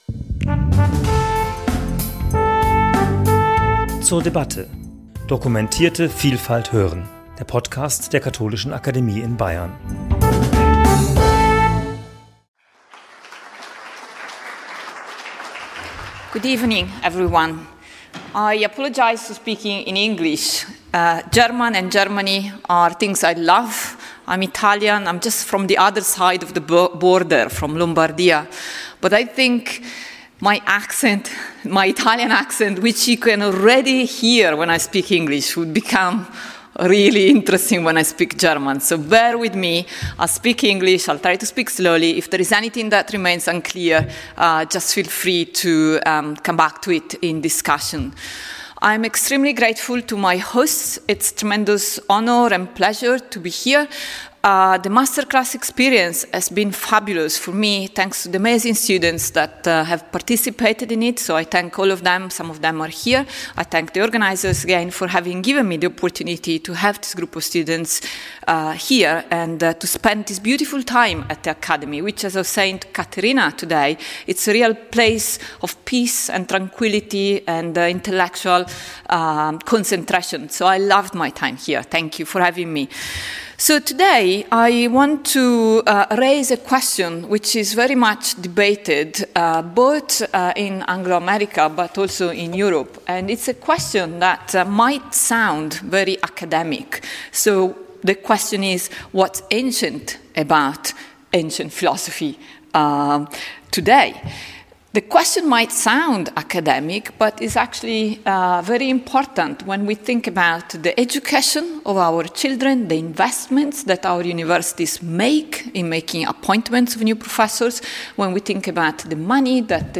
In this talk, I engage with the multifaced debate on the topic that continues today, examining positions and arguments. I conclude with a positive proposal for why studying and researching ancient philosophy today can make us better philosophers if our concerns are with today’s world, but also better scholars if our interests lie with historical ideas.